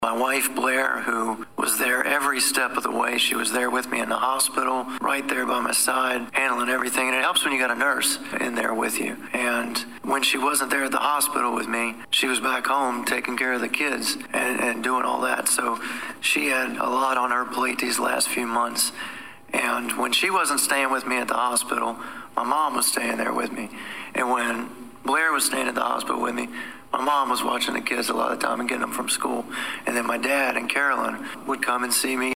Just under three months after he was attacked and set on fire Danville City Councilman Lee Vogler attended his first city council meeting since the attack tonight.